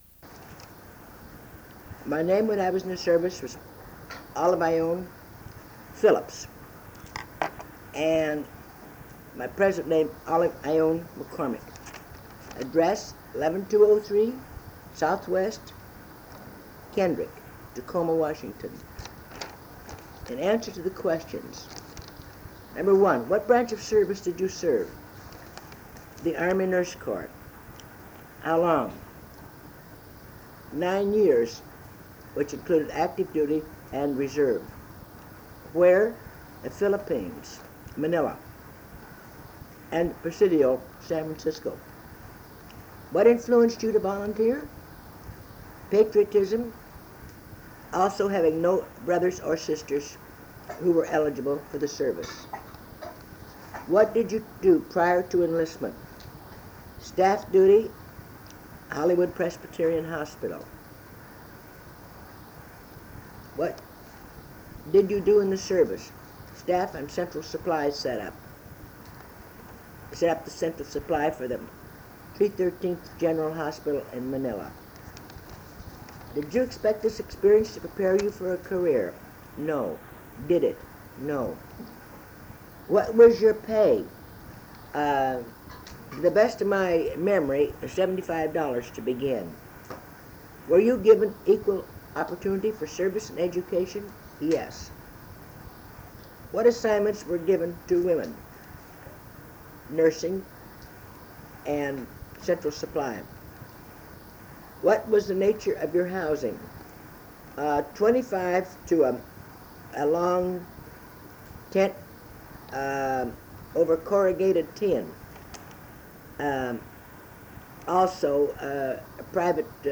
Oral History Project
Speeches (compositions)